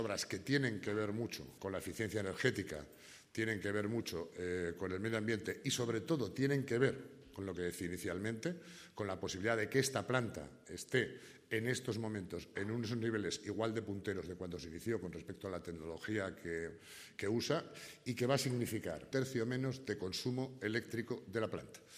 Este proyecto se realiza en una planta pionera de la segunda generación de depuradoras, que se construyó hace 20 años con tecnología punta, todavía vanguardista, pero en la que se interviene para lograr mayor eficiencia energética, ahorros en la factura eléctrica (en el entorno de 560.000euros al año) además de un menor coste de mantenimiento. Así lo explica el Consejero de Urbanismo, Infraestructuras, Equipamientos y Vivienda del Ayuntamiento de Zaragoza, Carlos Pérez Anadón.